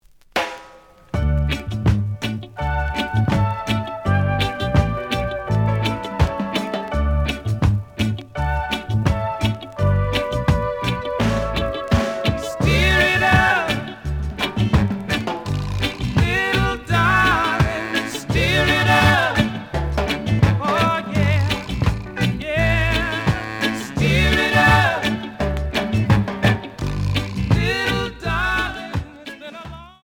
The audio sample is recorded from the actual item.
●Genre: Reggae